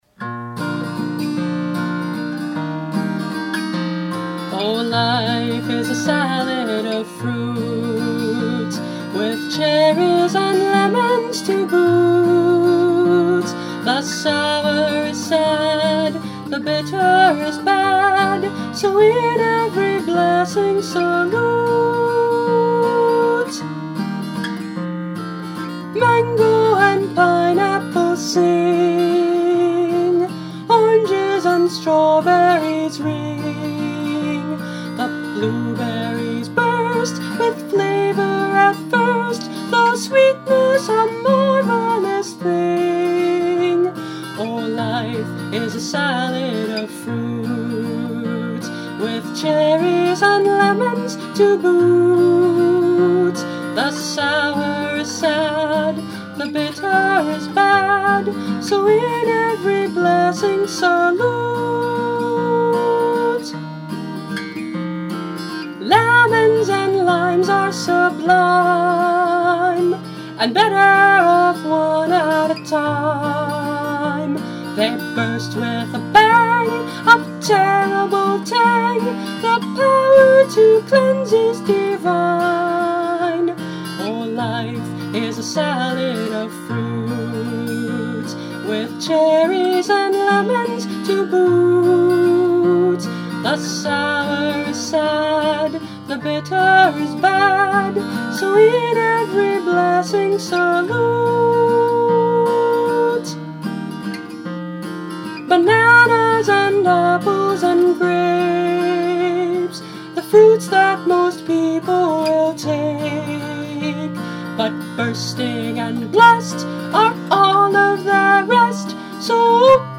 creating a song with a light, but important message.
Instrument: Tempo – Seagull Excursion Folk Acoustic Guitar
(capo 3)